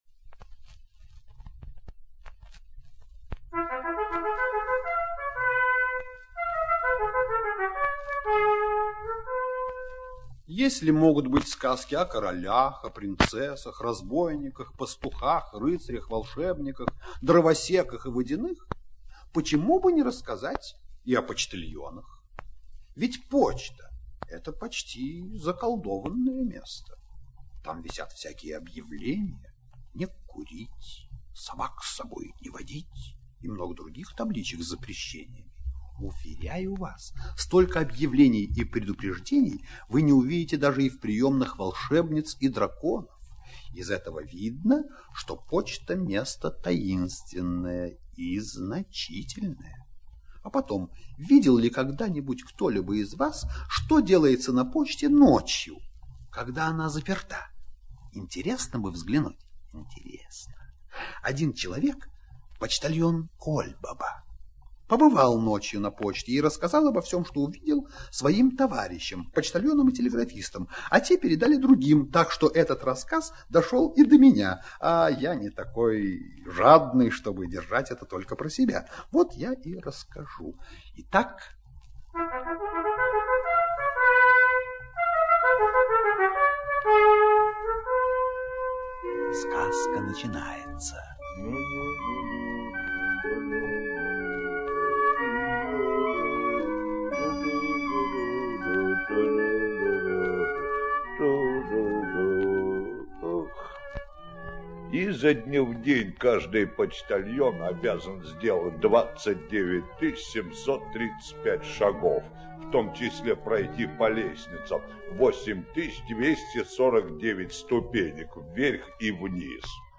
Сказка о почтальоне - аудиосказка Чапека - слушать онлайн